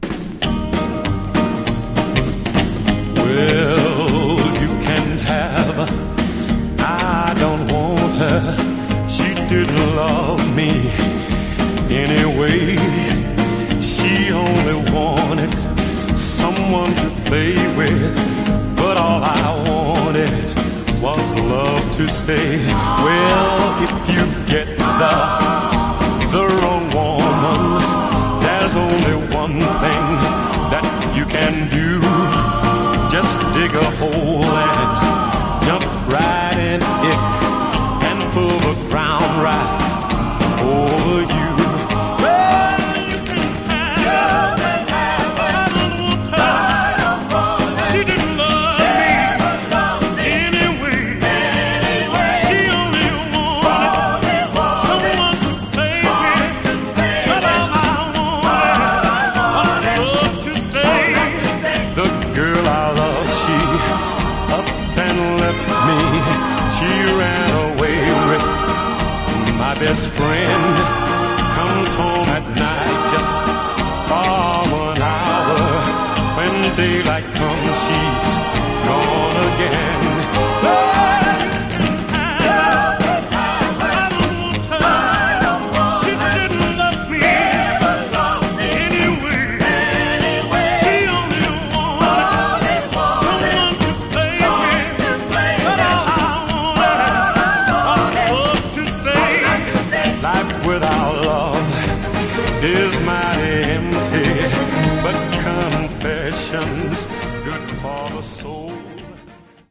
Genre: Deep Soul
a terrific Deep Soul number you don't want to be without!